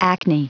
Prononciation du mot acne en anglais (fichier audio)
Prononciation du mot : acne